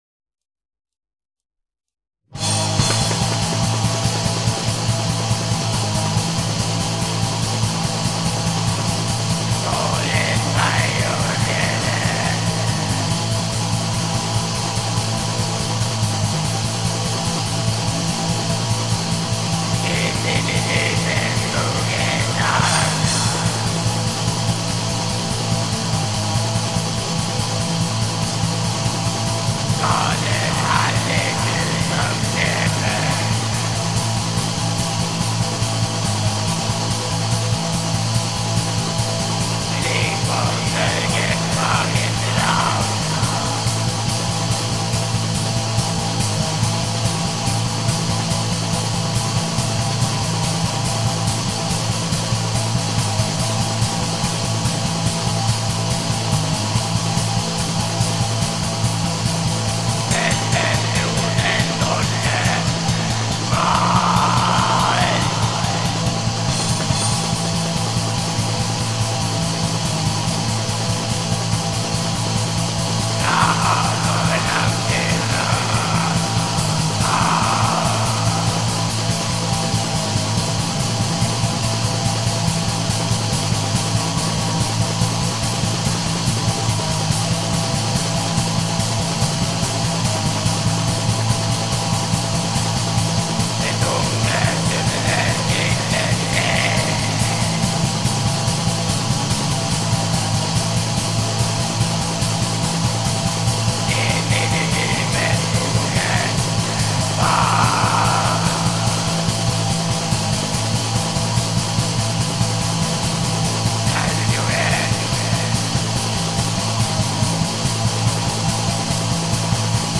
بلک متال